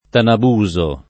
vai all'elenco alfabetico delle voci ingrandisci il carattere 100% rimpicciolisci il carattere stampa invia tramite posta elettronica codividi su Facebook Tanabuso [ tanab 2@ o ] soprann. m. — personaggio di A. Manzoni: uno dei bravi di don Rodrigo